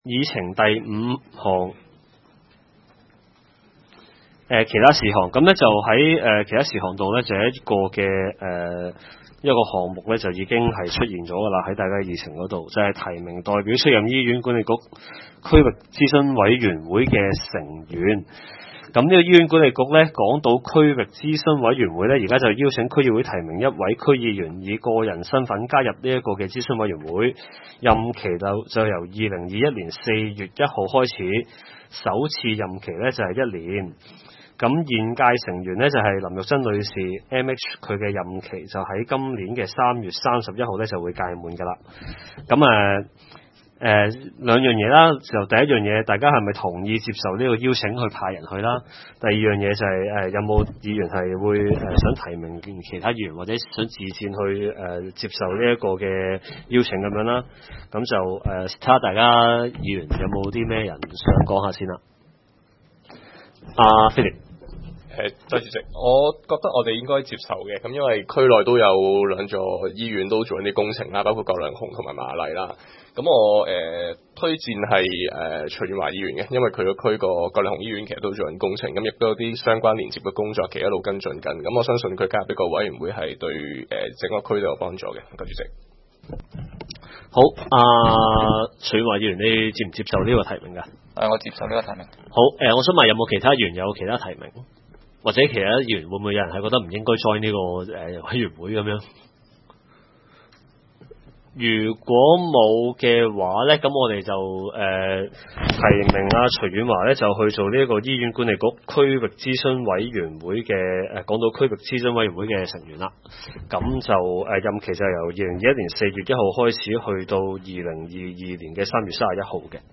南区区议会大会的录音记录
南区区议会会议室